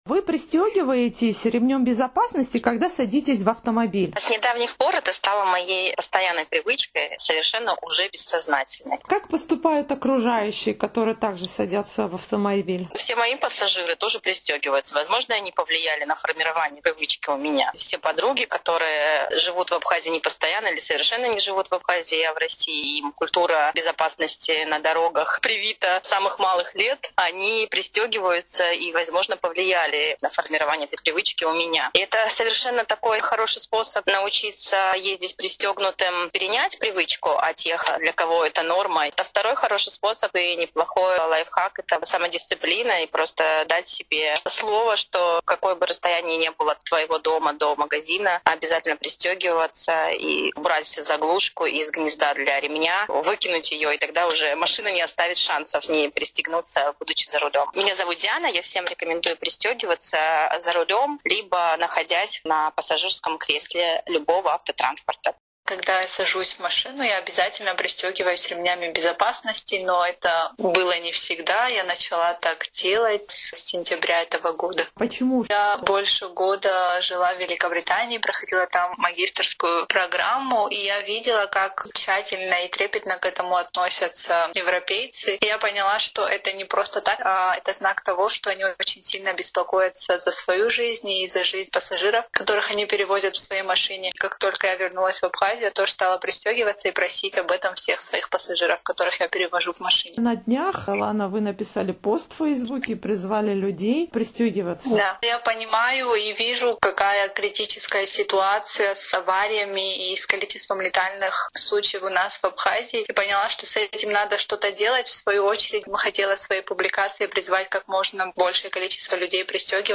В Абхазии после череды аварий с летальным исходом пользователи соцсетей объявили флешмоб «Пристегнись, нас ждут дома». «Эхо Кавказа» поинтересовалось у жителей республики, пристегивают ли они ремень безопасности, когда садятся в автомобиль.